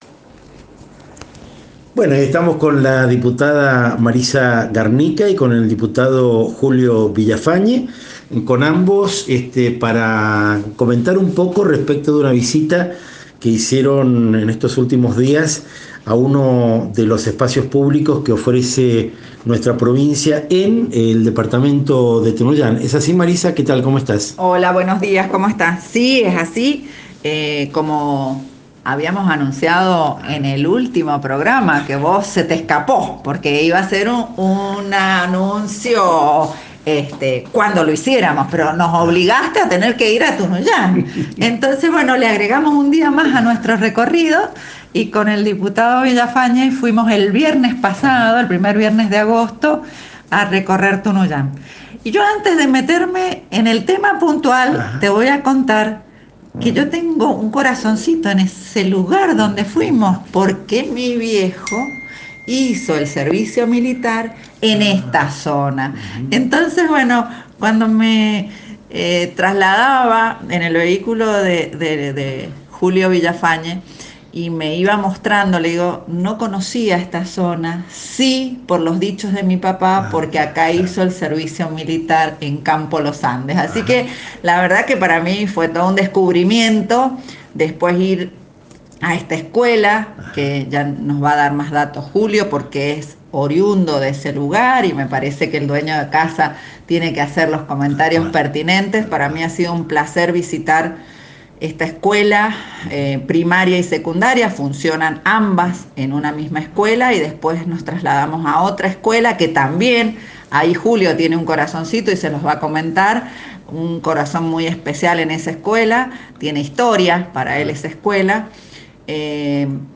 Entrevista: Marisa Garnica y Julio Villafañe, diputados provinciales, 10 de agosto de 2022